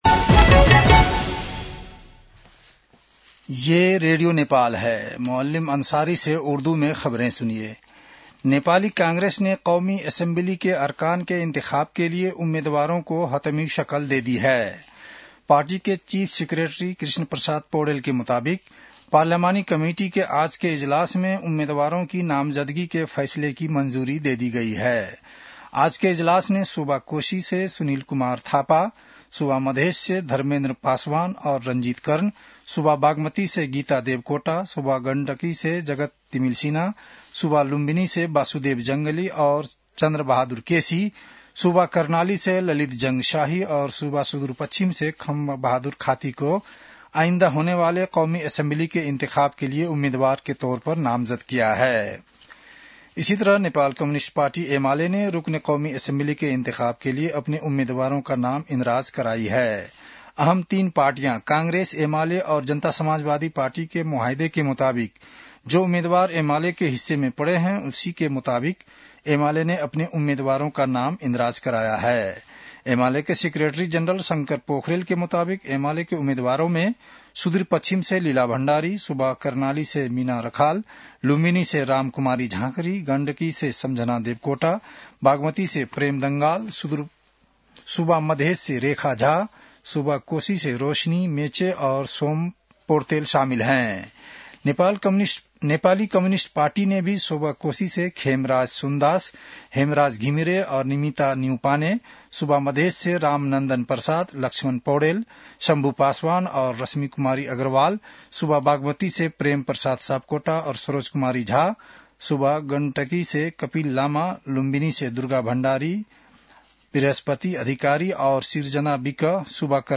उर्दु भाषामा समाचार : २३ पुष , २०८२
URDU-NEWS-09-23.mp3